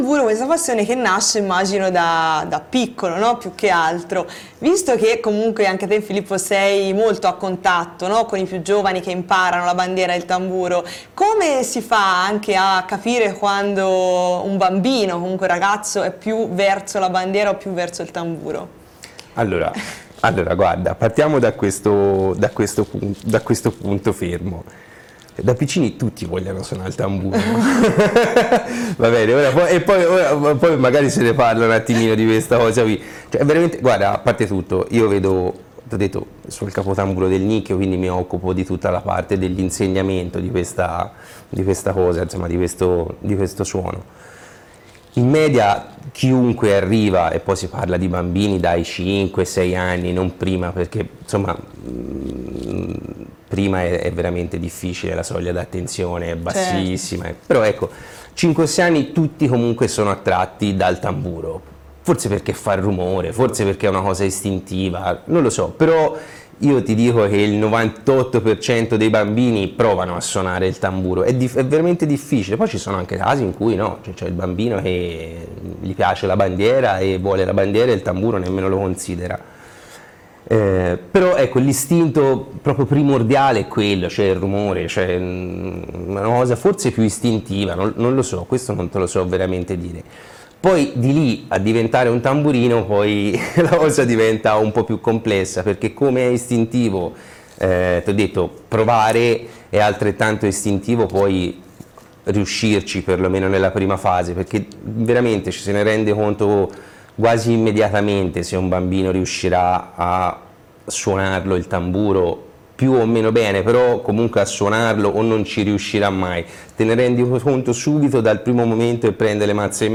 Tipologia di parlato: intervista televisiva in diretta (emittente locale: dicembre 2018). Argomento del brano: come insegnare ai bambini l’attività di tamburino per il Palio.
Per quanto riguarda la spirantizzazione, in un quadro in cui il dileguo di -k- pare sottoposto a controllo, è notevole la sequenza [di 'westa 'ɔza 'ʰwi] ‘di questa cosa qui’.